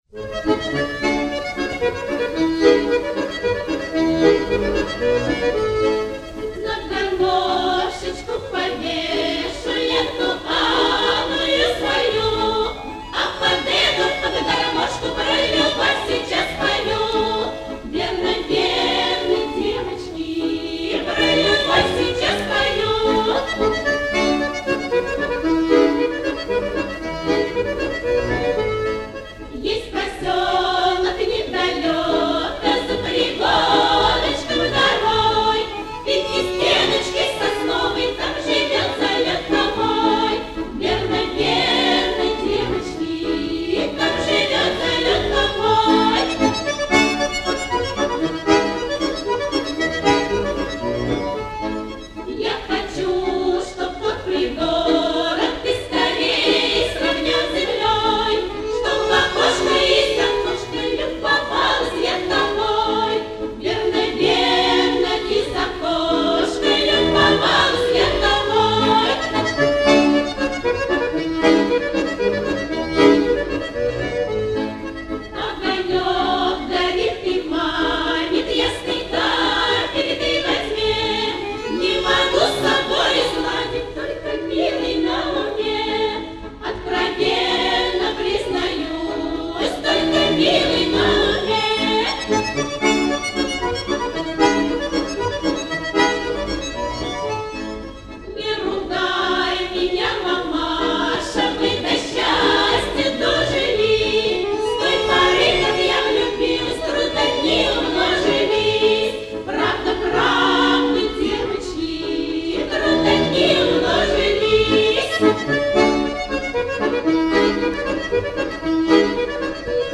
Матрица 36361 (патефонная)